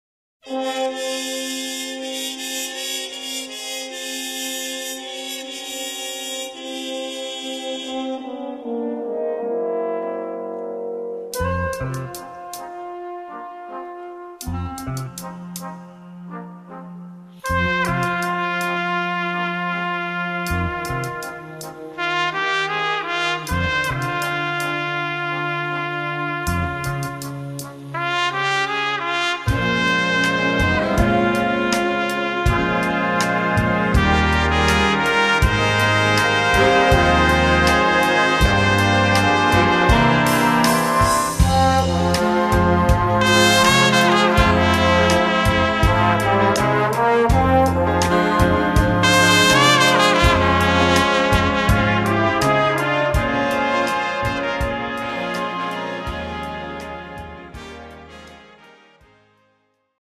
Besetzung: Instrumentalnoten für Trompete